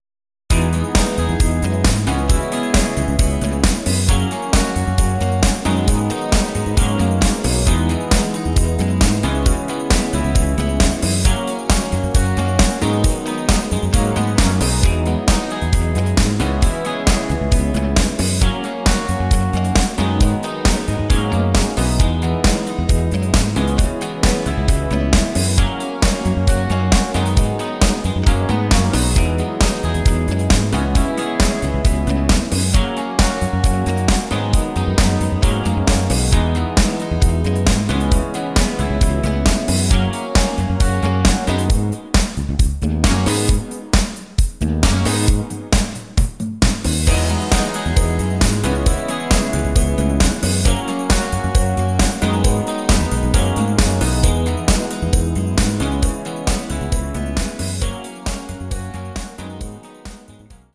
Rhythmus  Discofox
Art  Englisch, Internationale Popmusik, Neuerscheinungen